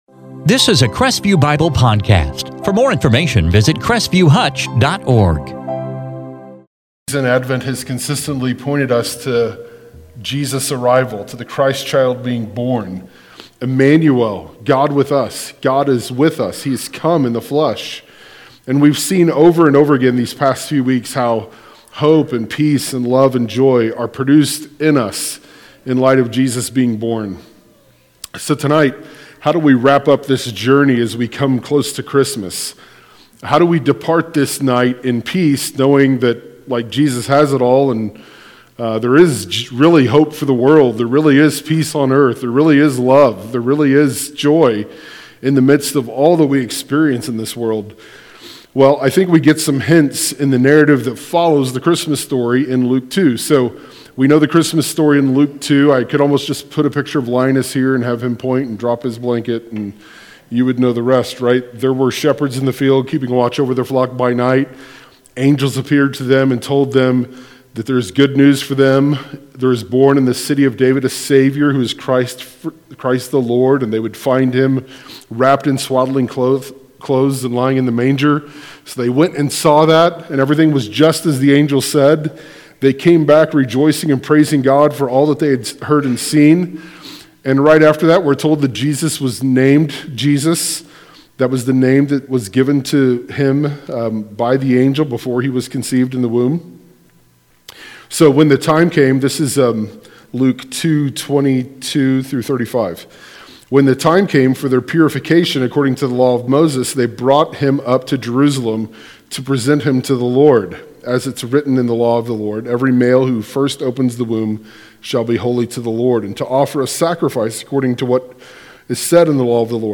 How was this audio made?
Christmas Eve 2021